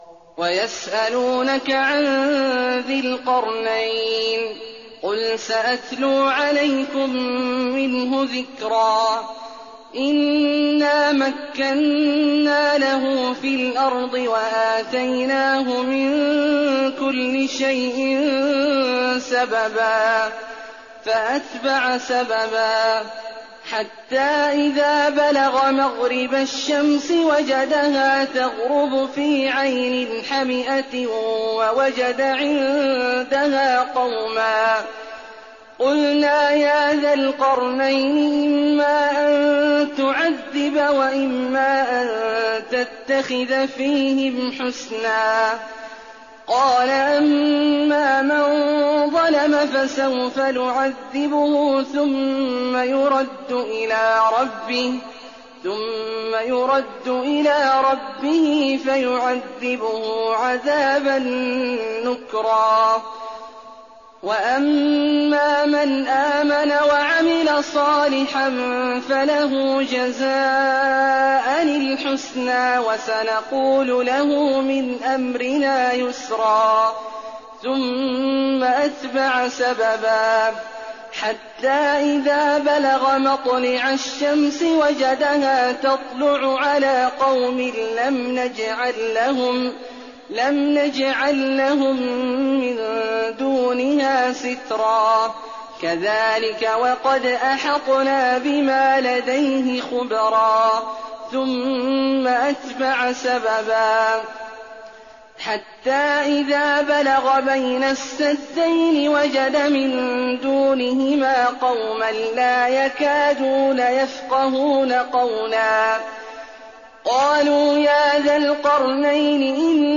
تراويح الليلة الخامسة عشر رمضان 1419هـ من سورتي الكهف (83-110) و مريم كاملة Taraweeh 15th night Ramadan 1419H from Surah Al-Kahf and Maryam > تراويح الحرم النبوي عام 1419 🕌 > التراويح - تلاوات الحرمين